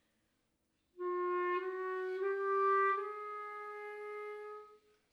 on historical clarinet
example of throat register